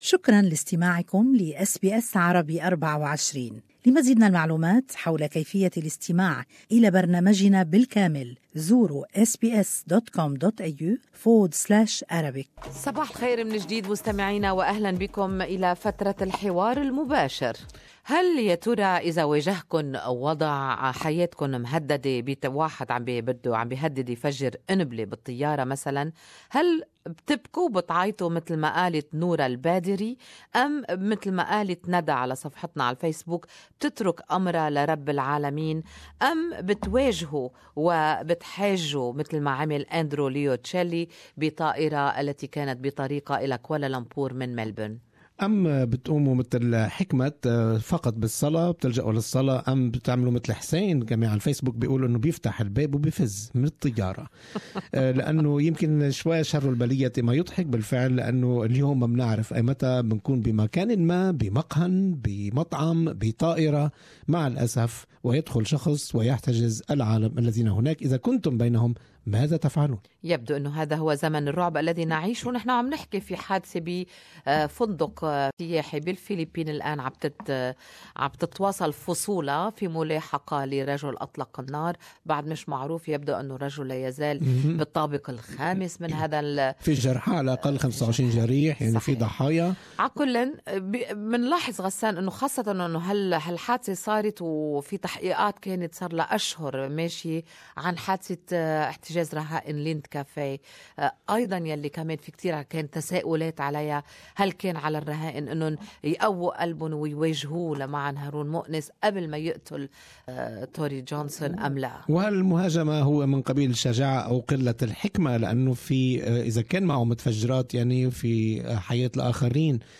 Talkback: What do you do if found in a risky situation?
The talkback hosts used the incident where an economy-class passenger tried to enter the cockpit of Malaysia Airlines plane en route to Kuala Lumpur returned to Melbourne and threatened to bomb to ask audience questions on what do you do if found in a risky situation.